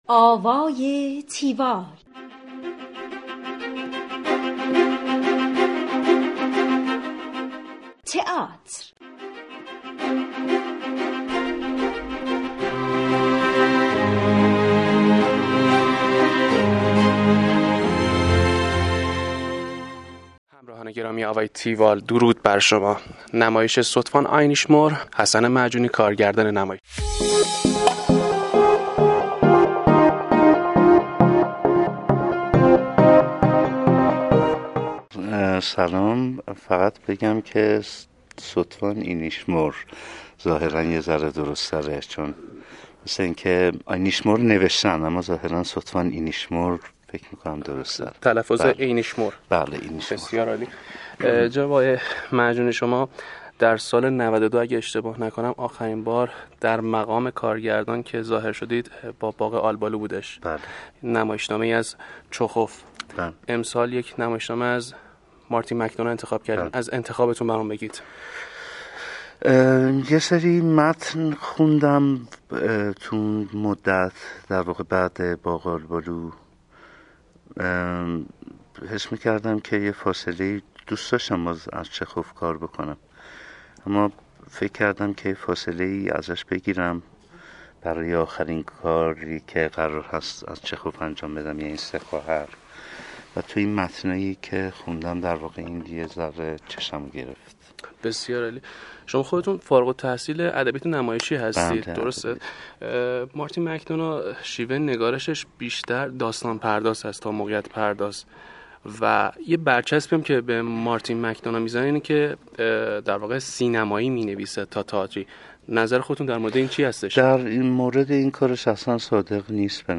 گفتگوی تیوال با حسن معجونی